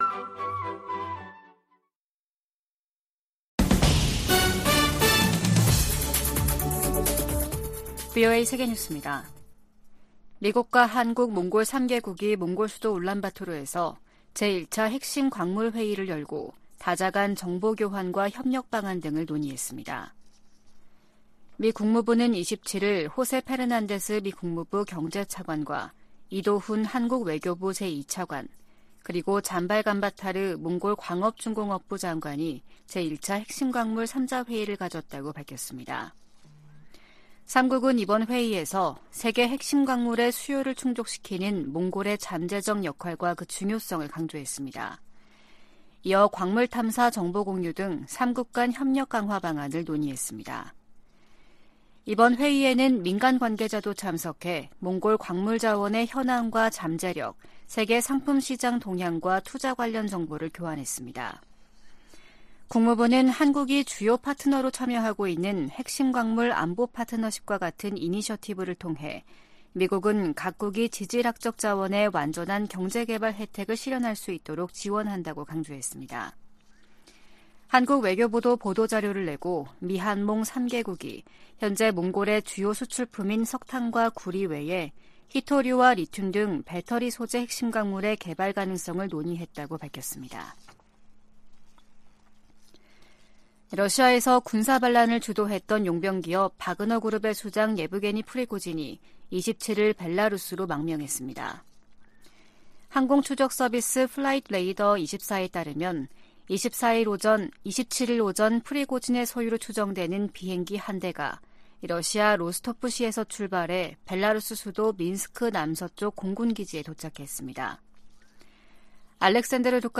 VOA 한국어 아침 뉴스 프로그램 '워싱턴 뉴스 광장' 2023년 6월 28일 방송입니다. 북한이 한반도 긴장 고조의 책임이 미국에 있다고 비난한데 대해 미 국무부는 역내 긴장을 고조시키는 건 도발이라고 반박했습니다. 미 국무부는 러시아 용병기업 바그너 그룹 무장 반란 사태가 바그너 그룹과 북한 간 관계에 미칠 영향을 판단하기는 이르다고 밝혔습니다. 백악관의 인도태평양조정관은 한국 등 동맹과의 긴밀한 관계가 인도태평양 전체의 이익에 부합한다고 말했습니다.